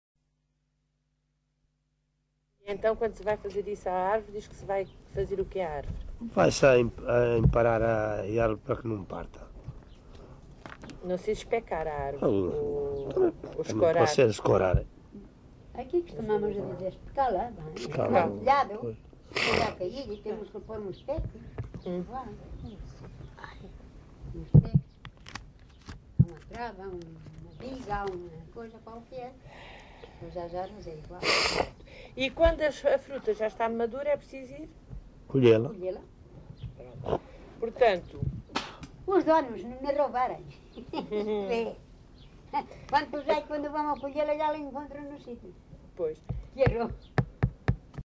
LocalidadeOuteiro (Bragança, Bragança)